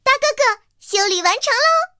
T-127修理完成提醒语音.OGG